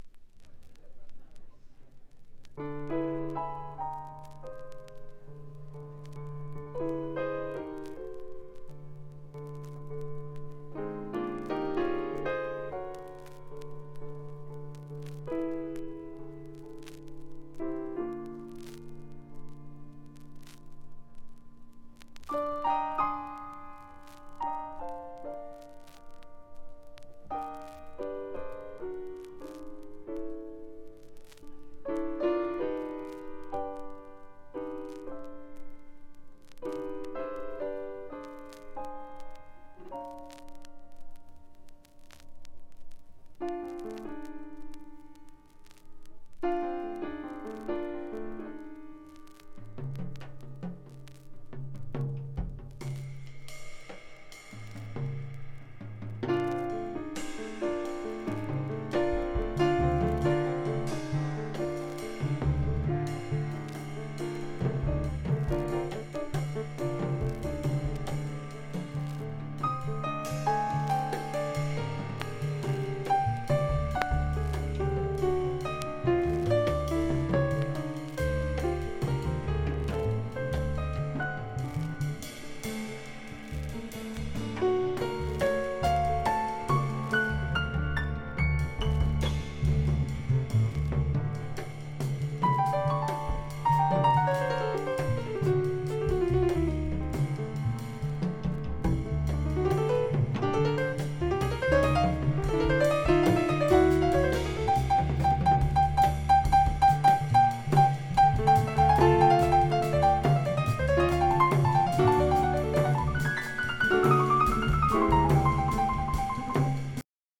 盤質：B+（見た目は悪くないですが、試聴のようにチリつくとこあり） ジャケット：天縁1cmヒビ、縁軽度しわ。